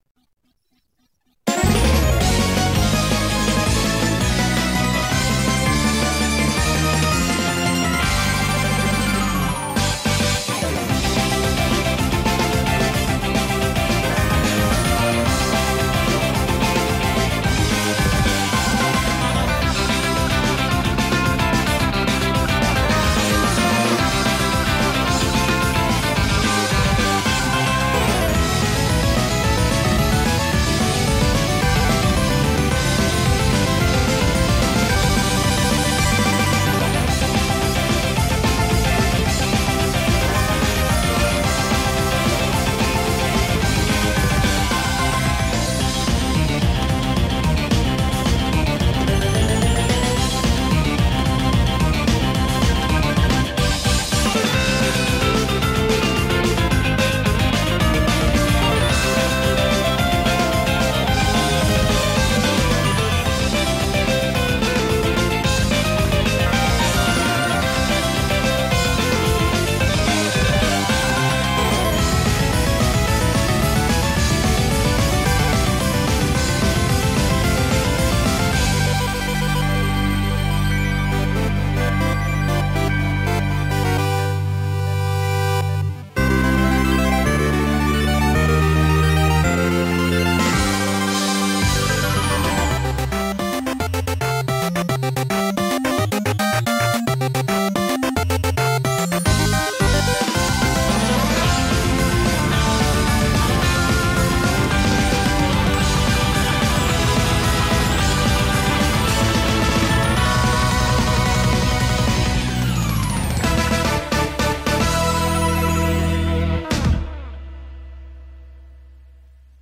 BPM110-220
Audio QualityLine Out